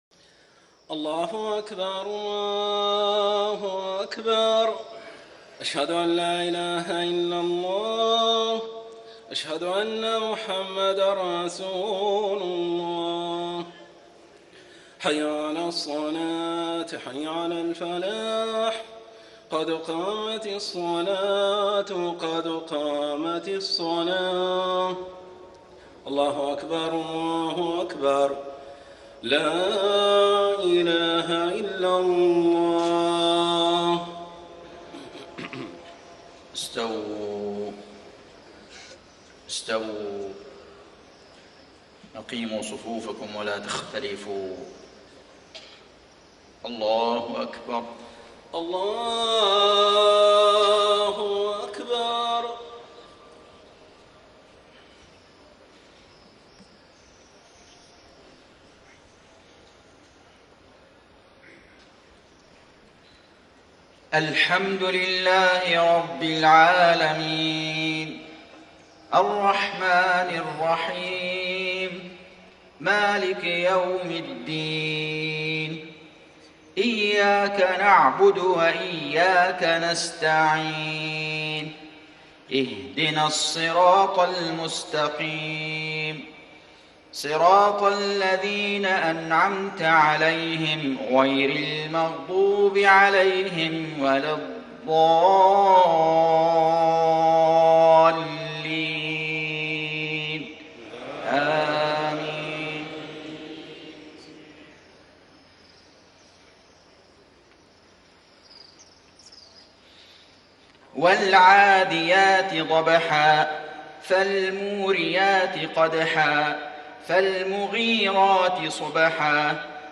صلاة المغرب 7-5-1435 سورتي العاديات و النصر > 1435 🕋 > الفروض - تلاوات الحرمين